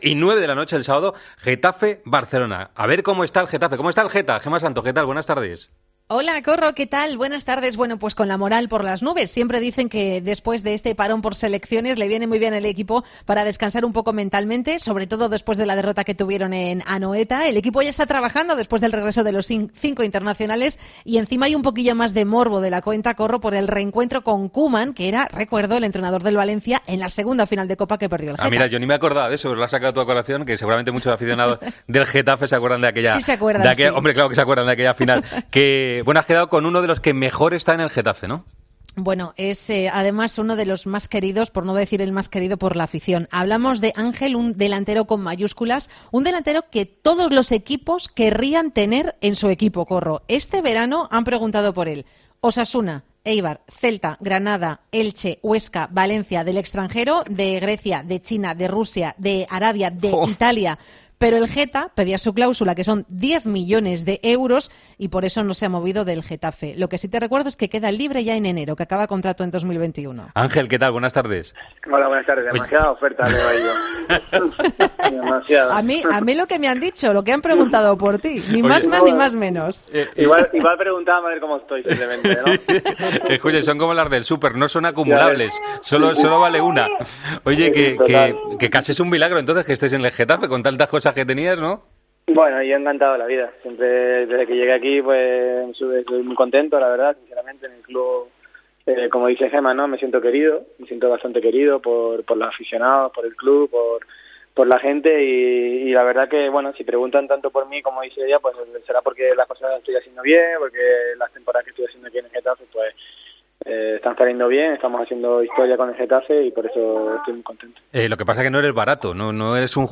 El jugador del Getafe, Ángel Rodríguez, estuvo este jueves en Deportes COPE analizando su momento actual en el equipo madrileño y también habló de sus ofertas en el pasado verano de otros equipos.